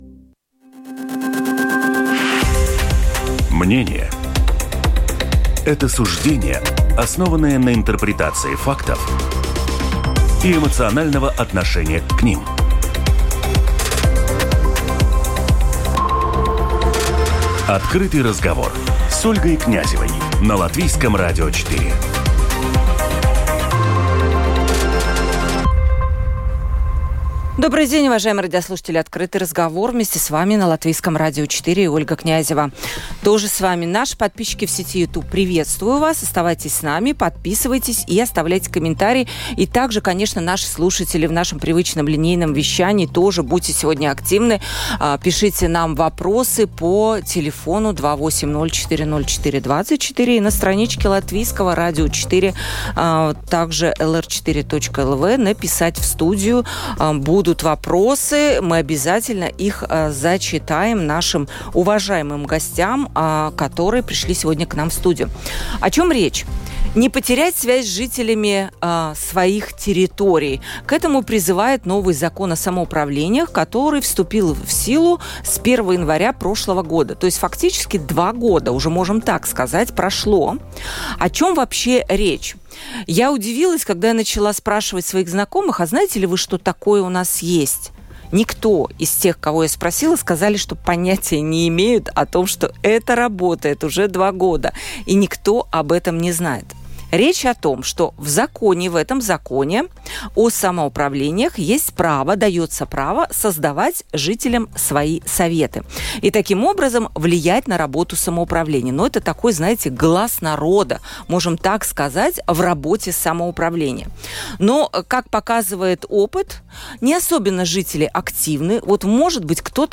Обсудим этот вопрос в очередной программе “Открытый разговор”.